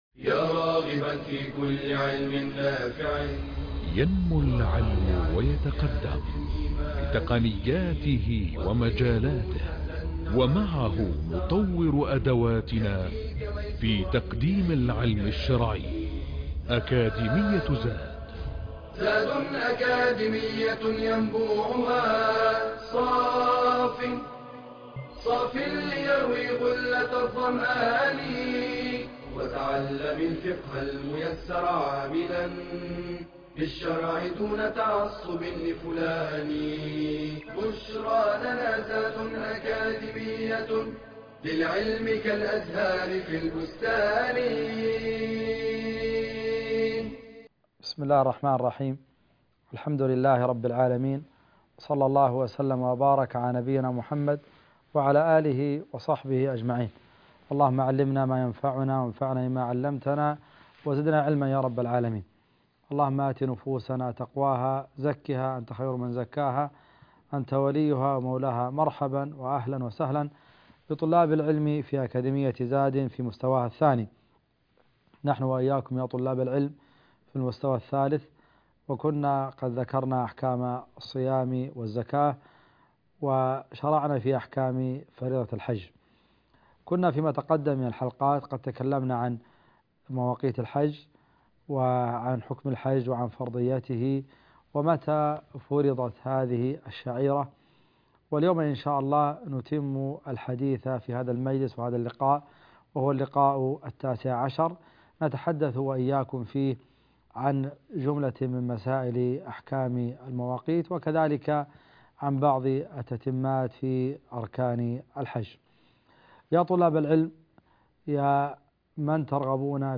المحاضرة التاسعة عشر -المواقيت المكانيه الحج